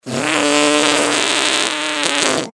Звуки пердежа
2 секунды протяжного пердежа